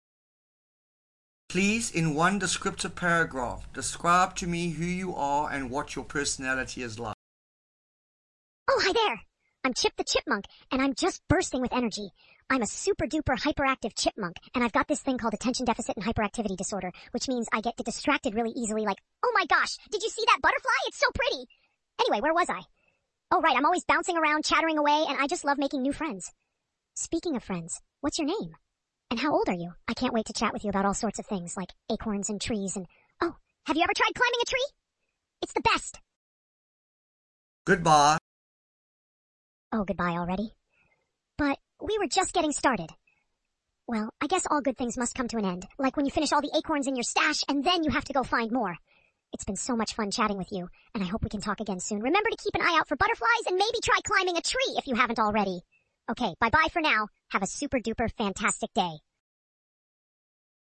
Voice ChatBots with Persona...